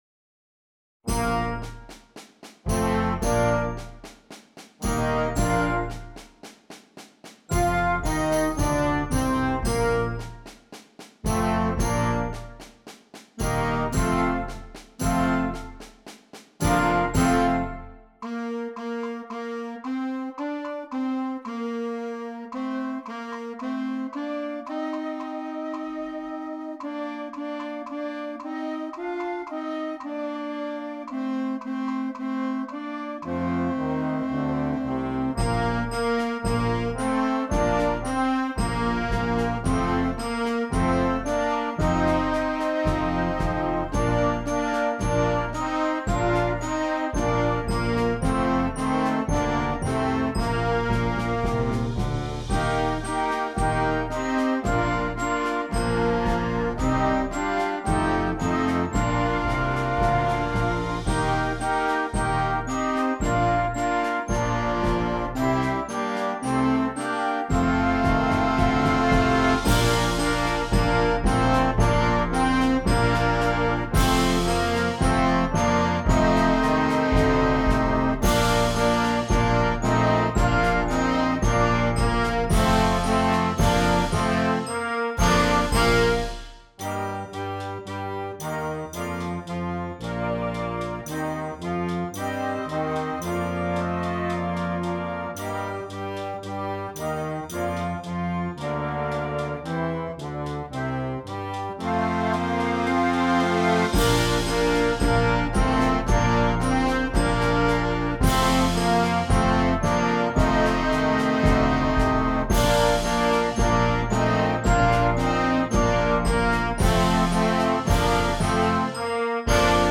Concert Band
Difficulty: Grade 0.5 Order Code